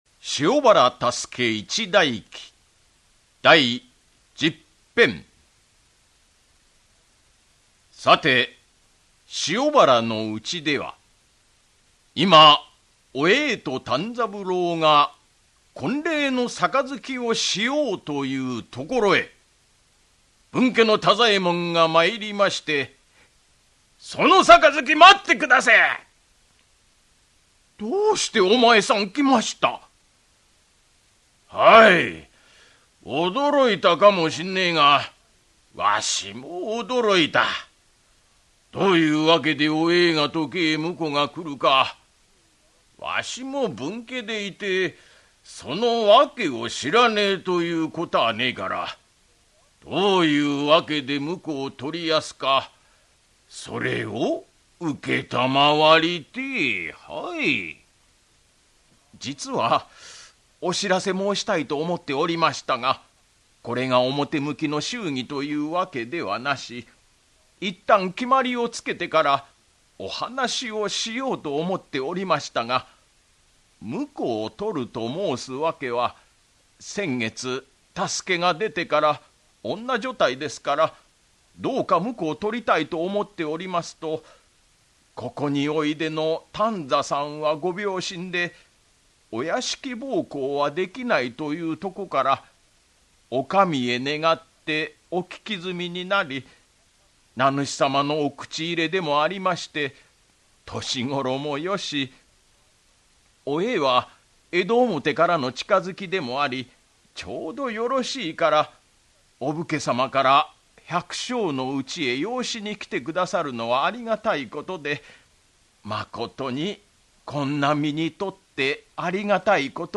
[オーディオブック] 塩原多助一代記-第十・十一編-
口演：